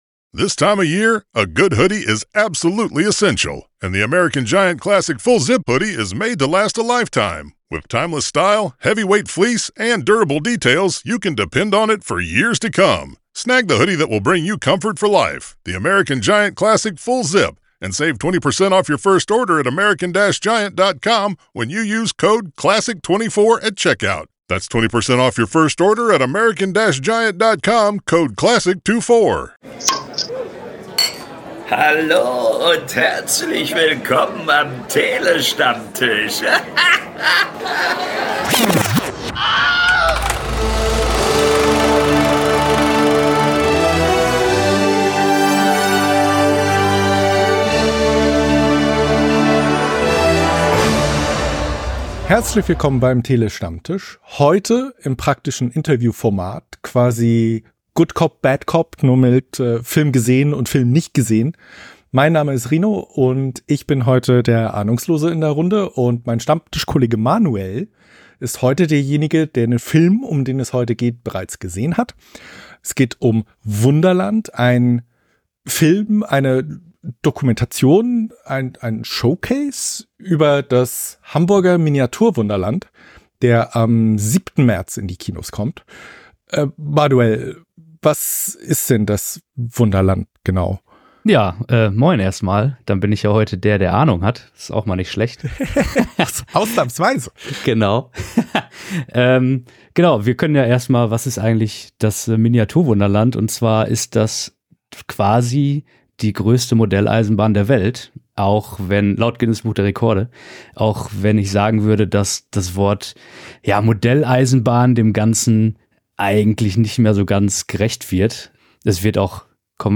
Trailer Wir liefern euch launige und knackige Filmkritiken, Analysen und Talks über Kino- und Streamingfilme und -serien - immer aktuell, informativ und mit der nötigen Prise Humor. Website | Youtube | PayPal | BuyMeACoffee Großer Dank und Gruß für das Einsprechen unseres Intros geht raus an Engelbert von Nordhausen - besser bekannt als die deutsche Synchronstimme Samuel L. Jackson!